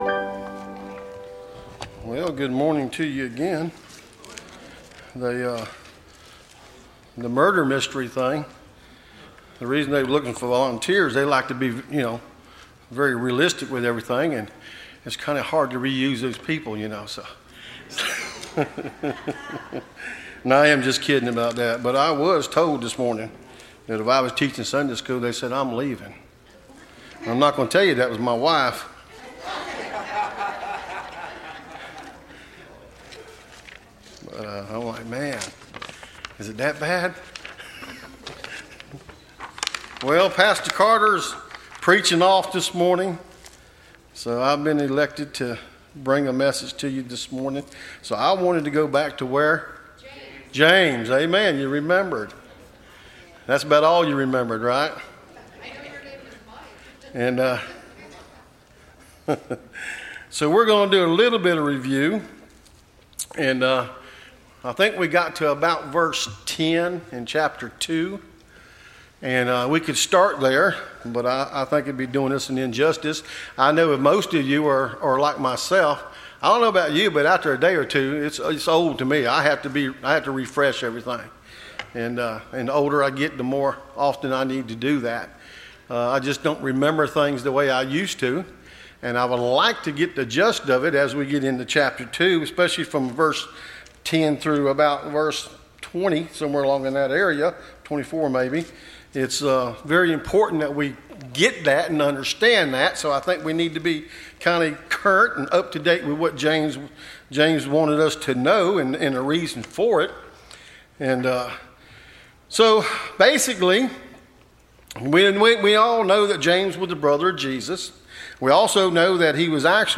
Listen to Message
Service Type: Sunday School